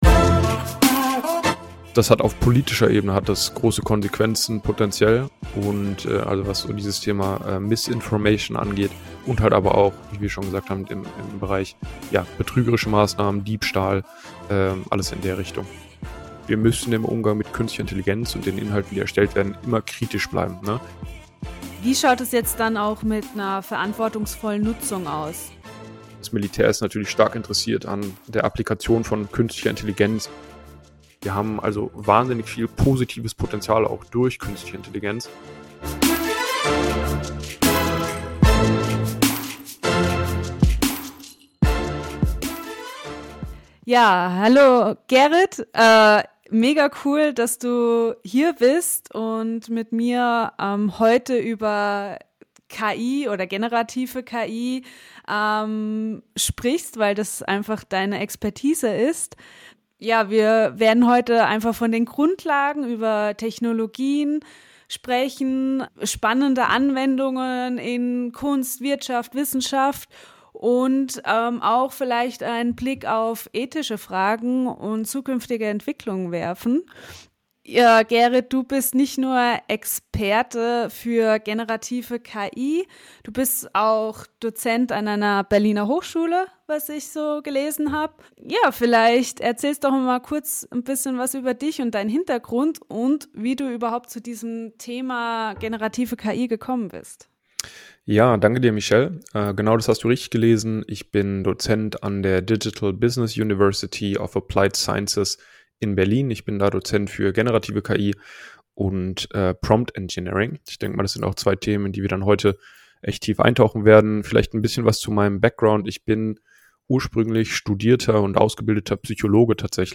Unser Gespräch beginnt bei den Grundlagen – wir erklären, was generative KI eigentlich bedeutet.